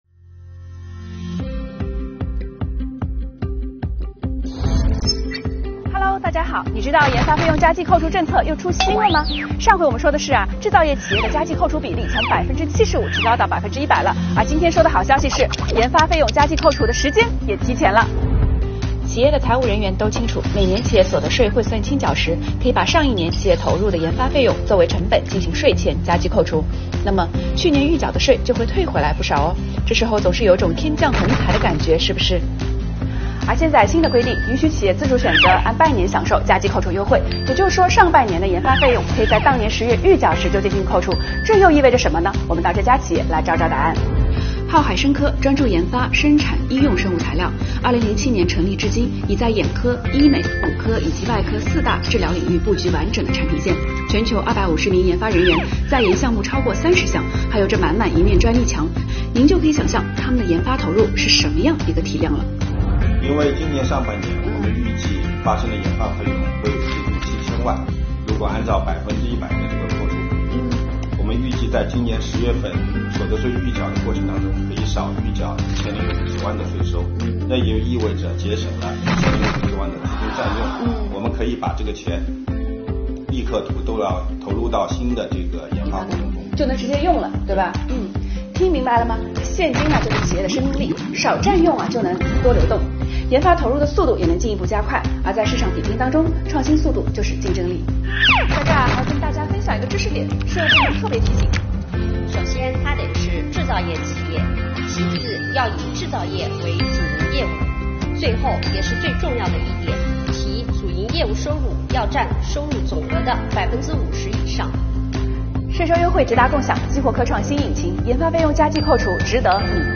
Vlog丨记者带你解热词：研发费用加计扣除又有好消息！企业可选择在10月预缴时享受优惠